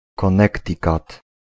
Ääntäminen
Ääntäminen US : IPA : [kə.ˈnɛɾ.ɪ.kət] Tuntematon aksentti: IPA : /kəˈnɛ.tə.kət/ Haettu sana löytyi näillä lähdekielillä: englanti Käännös Ääninäyte Erisnimet 1. Connecticut {n} Määritelmät Erisnimet A Capital: Hartford .